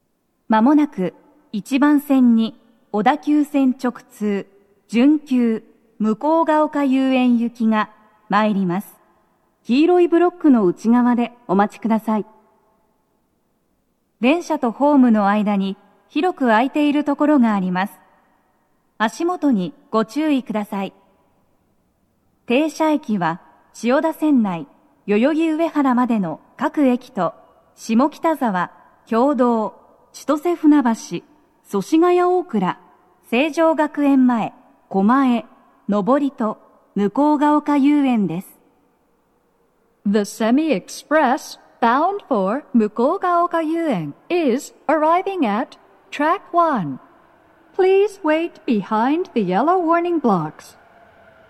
鳴動は、やや遅めです。鳴動中に入線してくる場合もあります。
接近放送5